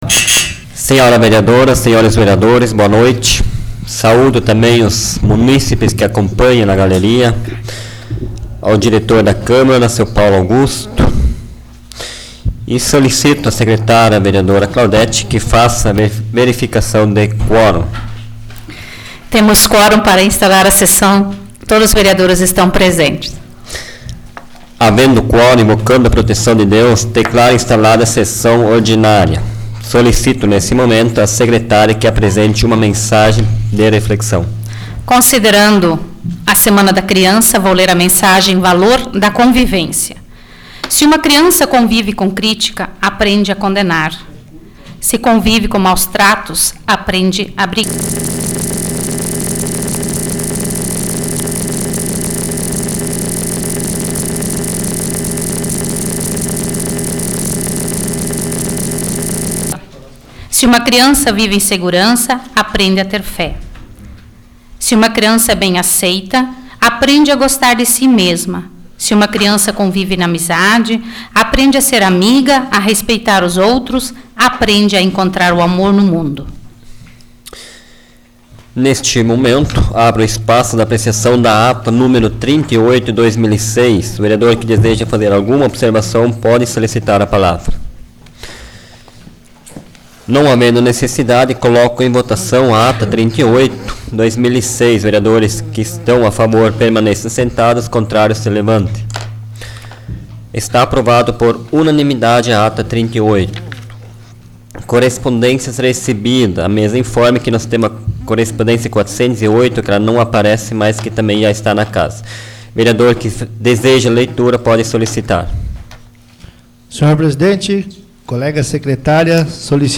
Áudio da 64ª Sessão Plenária Ordinária da 12ª Legislatura, de 09 de outubro de 2006